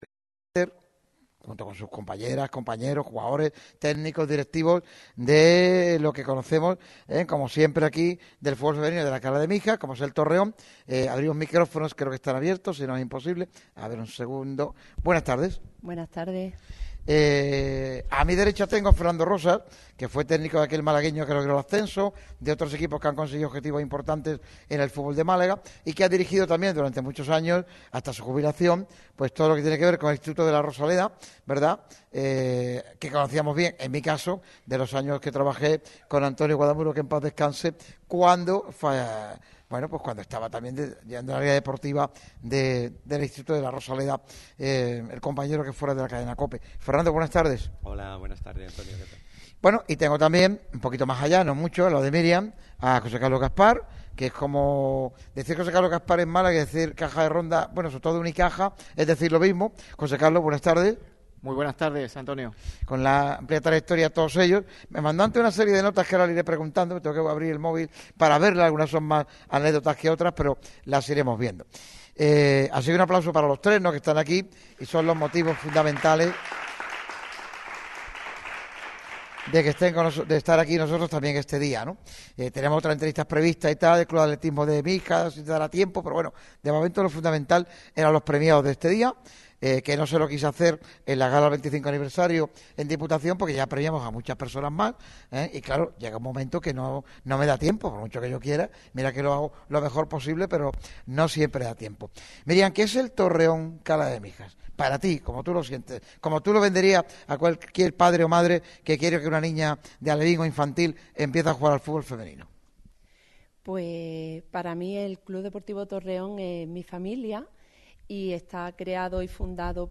Un programa donde los diferentes equipos del deporte de la localidad han pasado por el micrófono rojo.